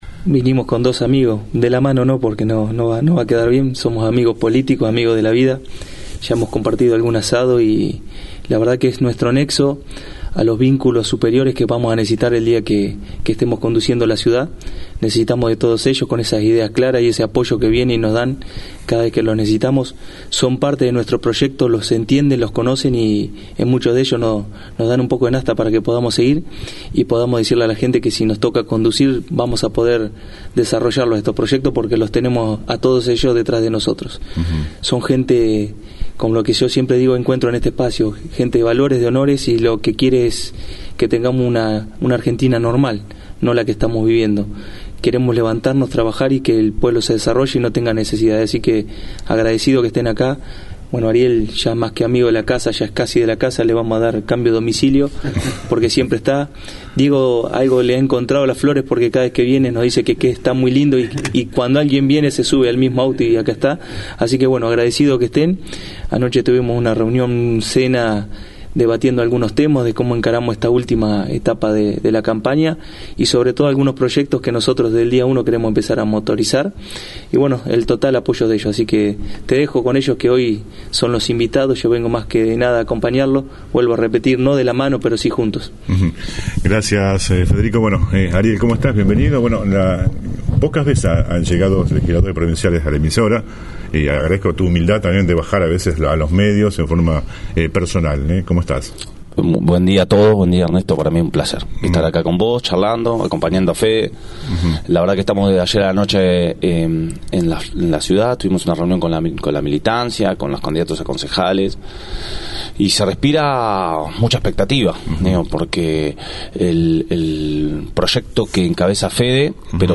En plena campaña electoral dirigentes de Juntos por el Cambio visitaron los estudios de FM ALPHA